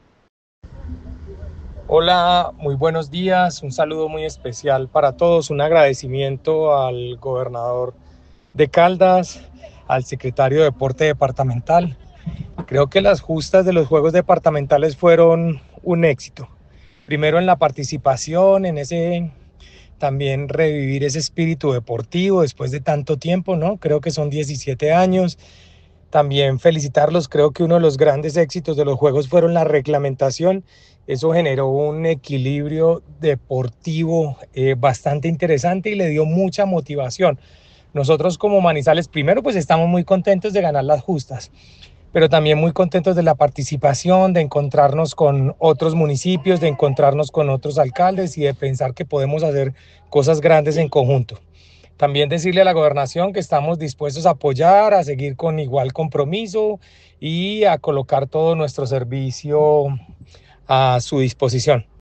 Diego Fernando Espinoza, secretario de Deporte de Manizales.
diego-fernando-espinoza-secretario-de-dporte-de-manizales.mp3